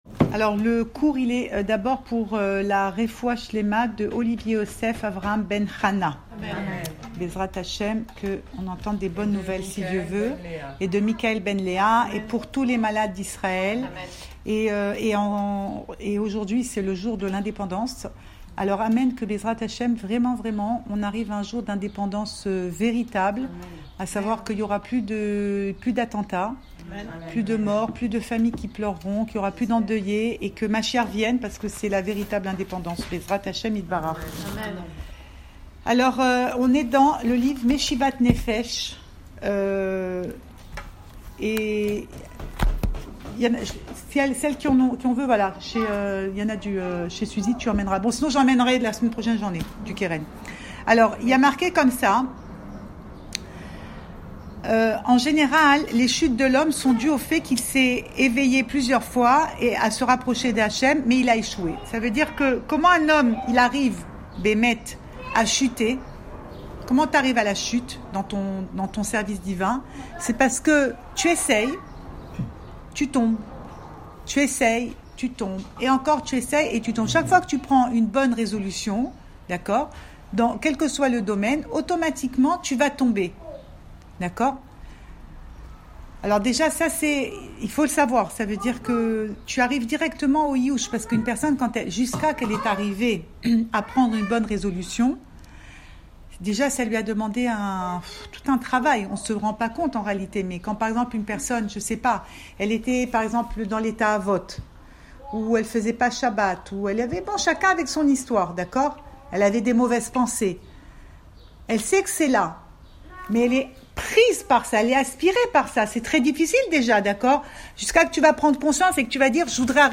Cours audio Le coin des femmes Pensée Breslev - 26 avril 2023 28 avril 2023 Indépendance ! Enregistré à Tel Aviv